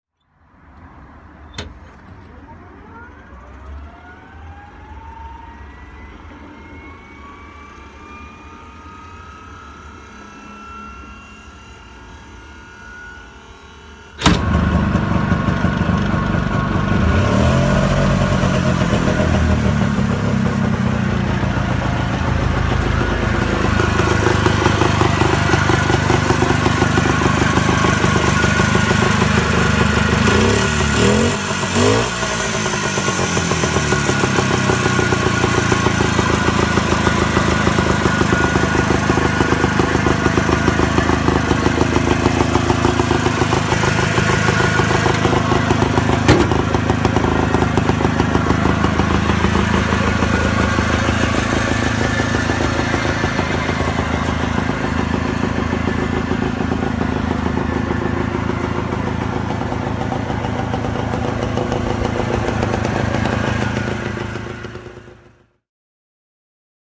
Its Westinghouse gyro starter, which acts as a starter via a flywheel, sounds exactly like a helicopter taking off. At least until the two-stroke engine comes to life (be sure to listen to the sound sample!).
Inter 175A Berline (1956) - Starten und Leerlauf